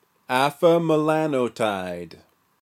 Pronunciation/ˌæfəmɛˈlæntd/
En-afamelanotide.oga.mp3